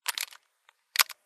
rptstungunreload.mp3